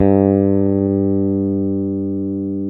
Index of /90_sSampleCDs/Roland L-CDX-01/BS _Jazz Bass/BS _Jazz Basses